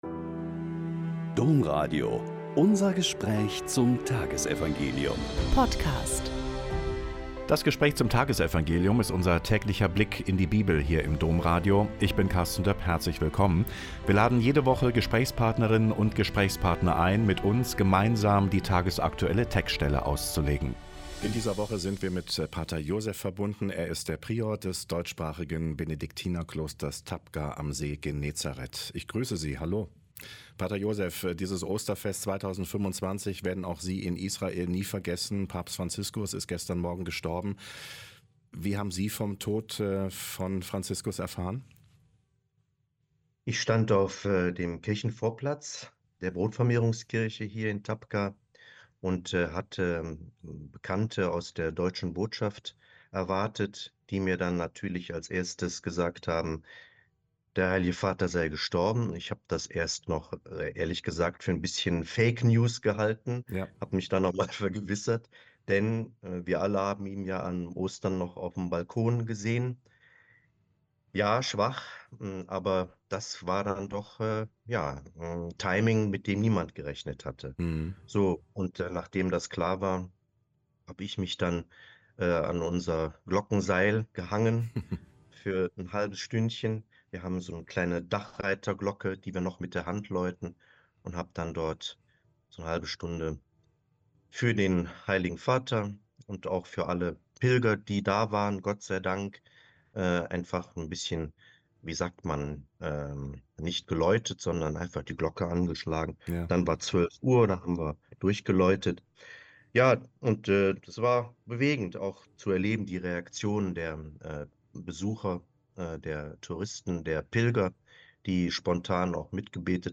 Mt 28,8-15 - Gespräch